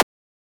bump.wav